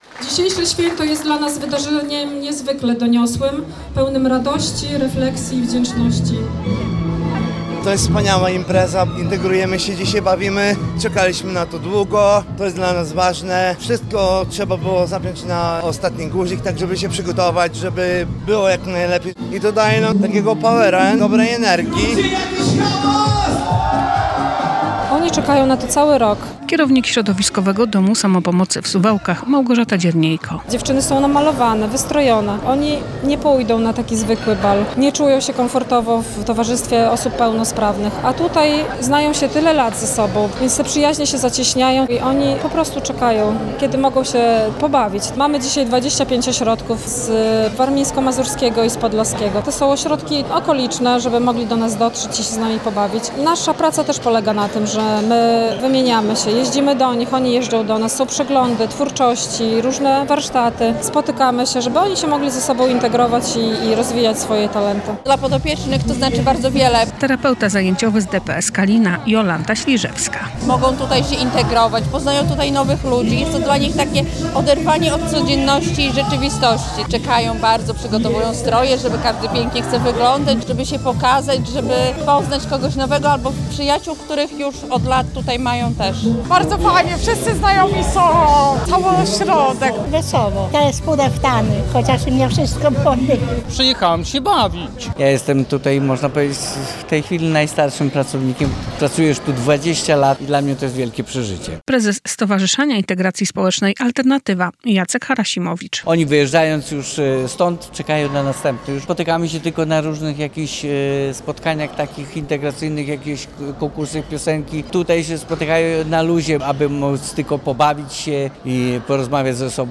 Blisko 800 osób z niepełnosprawnościami bawiło się na XVI Regionalnym Balu Andrzejkowym, zorganizowanym przez Stowarzyszenie Integracji Społecznej "Alternatywa" oraz Środowiskowy Dom Samopomocy w Suwałkach.